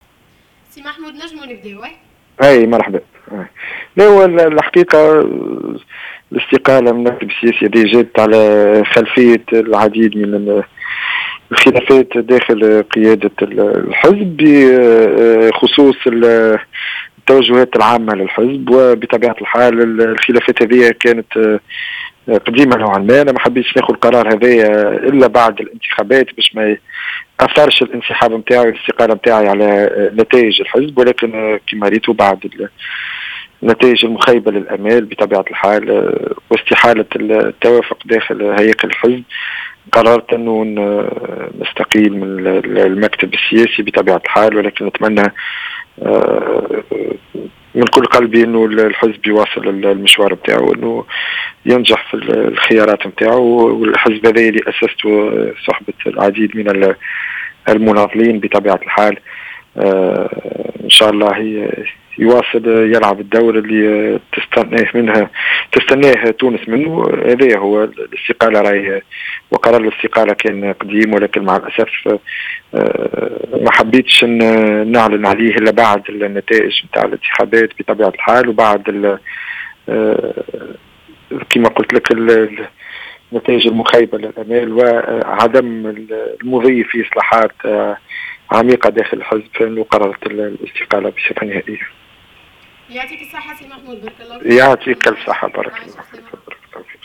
أكد عضو المكتب السياسي لحزب التحالف الديمقراطي محمود البارودي في تصريح لجوهرة "اف ام" اليوم الإثنين 3 اكتوبر 2014 استقالته من المكتب السياسي للحزب موضحا أن نية الاستقالة كانت موجودة لديه حتى قبل الانتخابات ولم يعلن عنها حتى لا يؤثر ذلك على الحزب في الانتخابات.